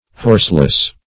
Horseless \Horse"less\, a.